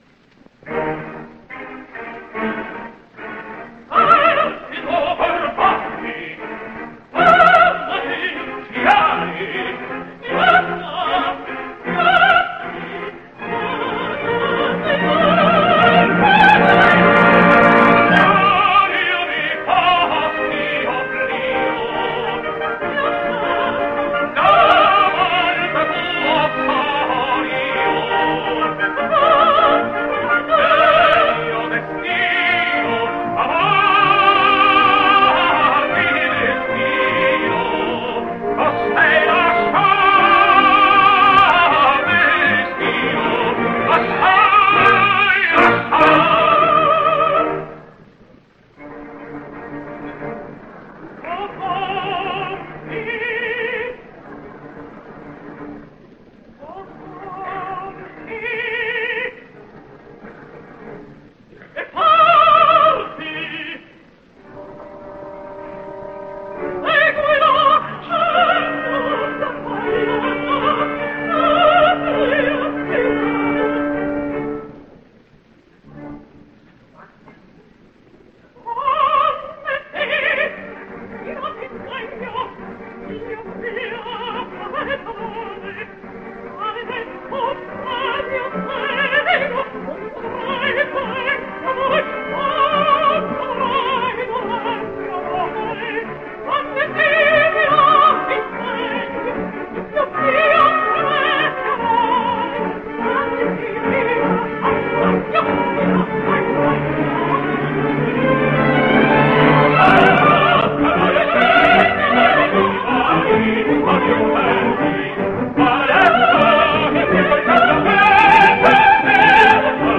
opera completa, registrazione dal vivo.